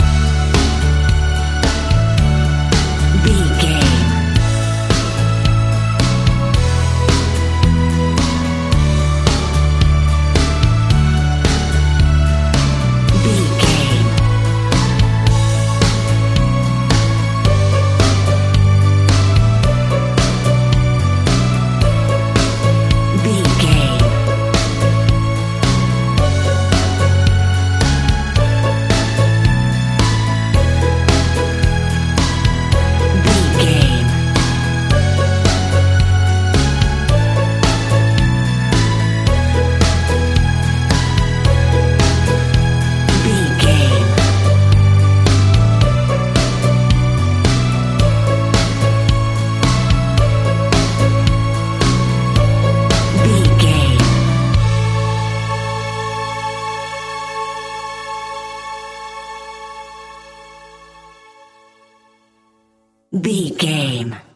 Ionian/Major
calm
melancholic
smooth
soft
uplifting
electric guitar
bass guitar
drums
strings
pop rock
indie pop
organ